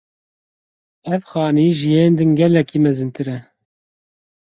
Pronúnciase como (IPA)
/xɑːˈniː/